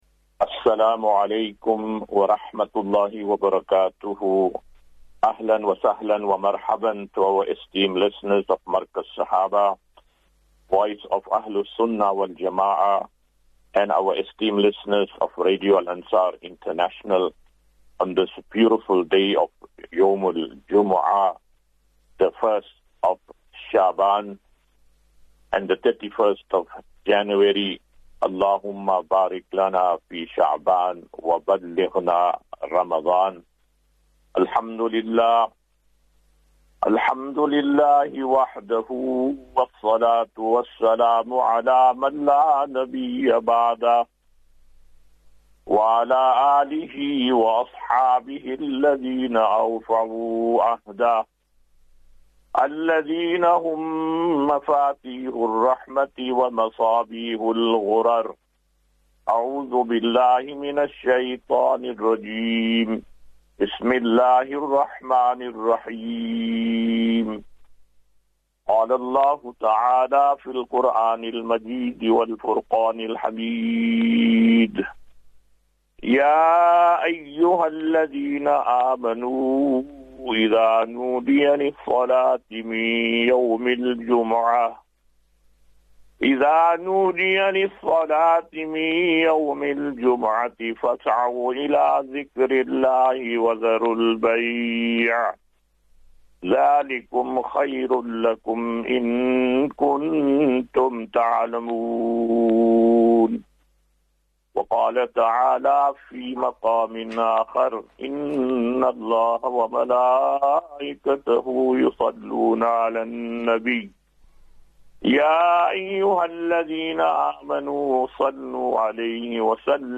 QnA